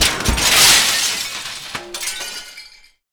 Index of /90_sSampleCDs/Roland L-CD701/PRC_Guns & Glass/PRC_Glass Tuned